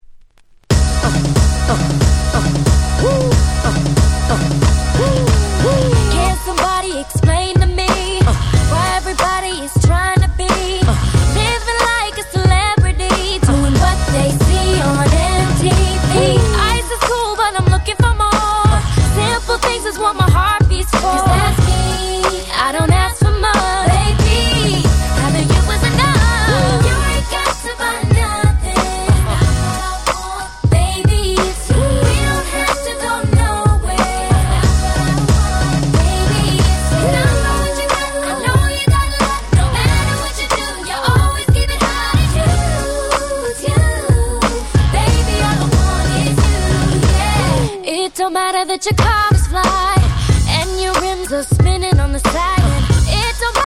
00's R&B